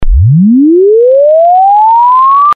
sinus_glissant - LASA
sinus_glissant.mp3